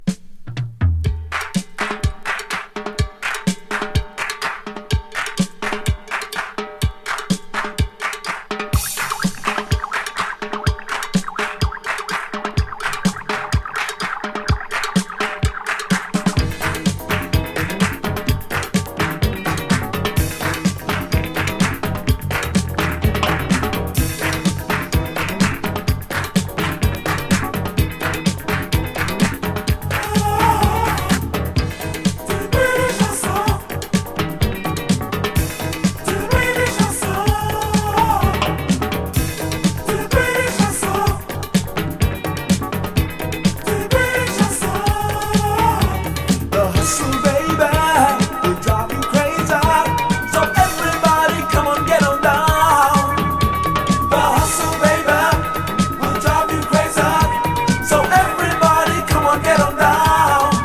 ブリティッシュ・ファンクグループ ’78年作！